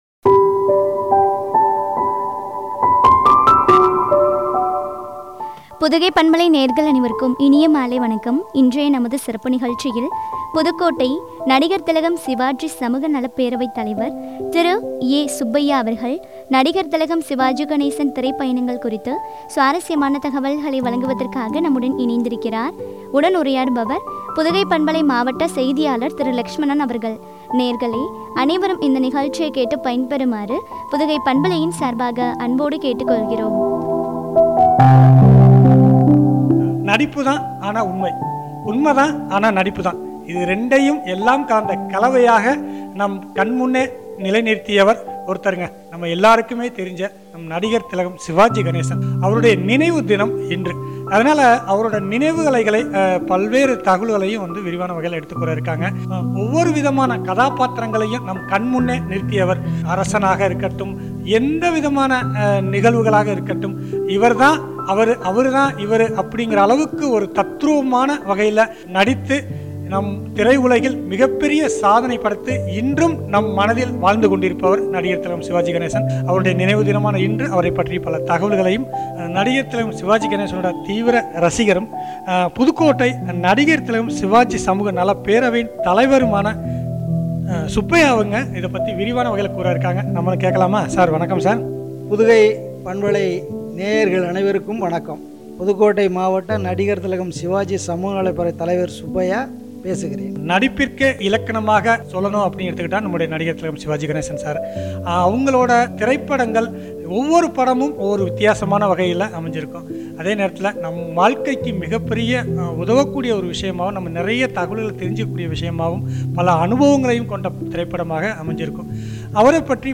உரையாடல்.